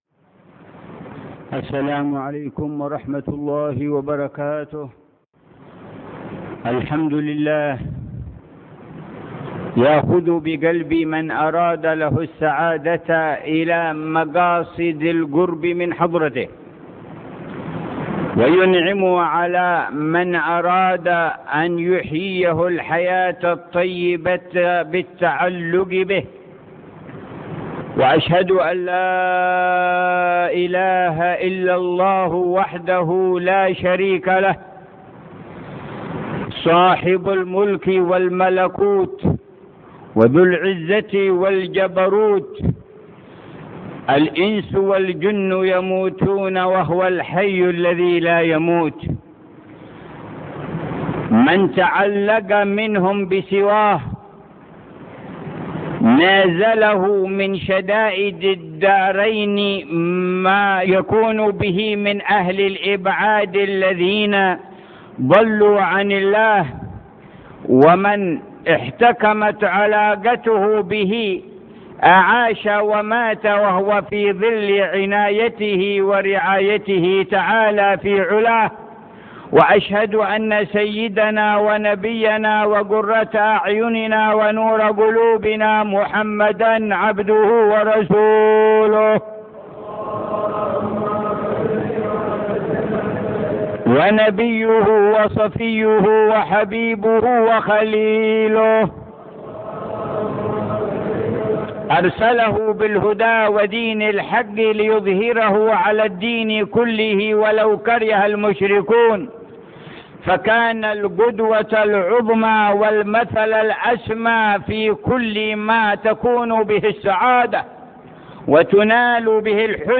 خطبة جمعة في جامع الروضة تريم - عيديد بتاريخ 25 شوال 1432هـ.